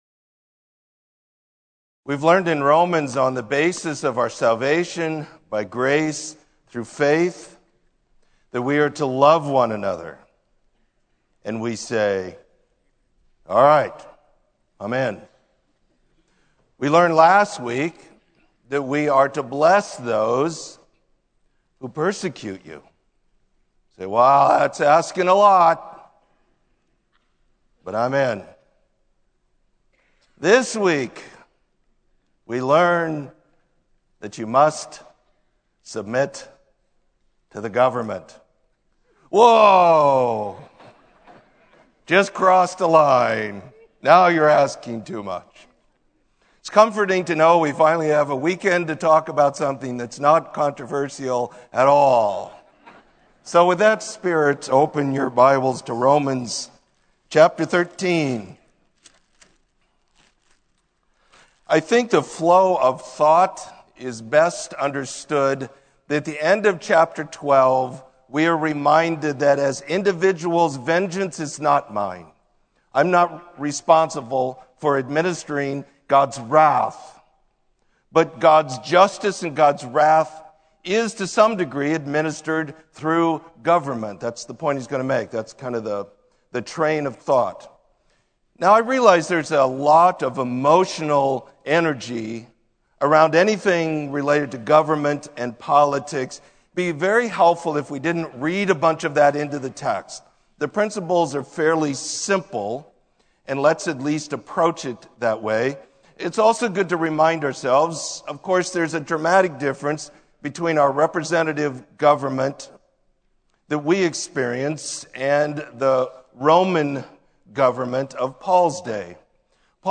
Sermon: Put on Christ